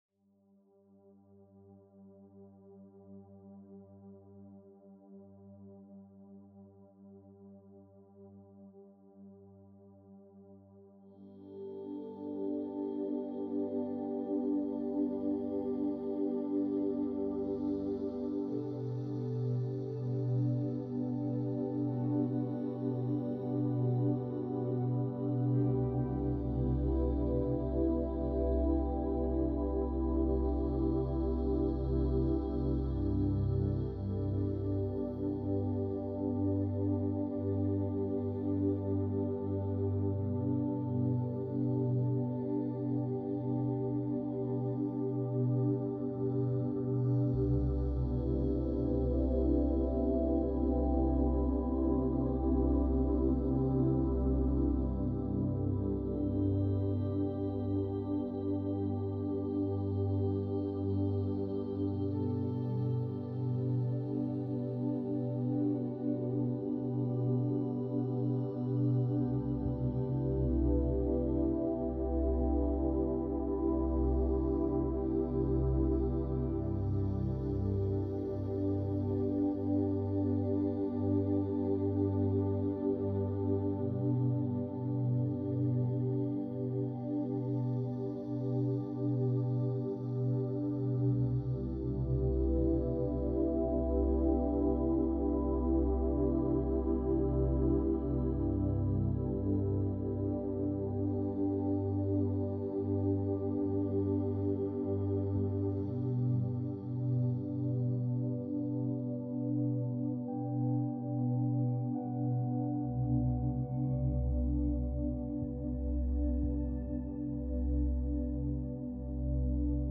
963hz - 10/10 Portal - Higher Self Activation ~ Binaural Beats Meditation for Sleep Podcast
Mindfulness and sound healing — woven into every frequency.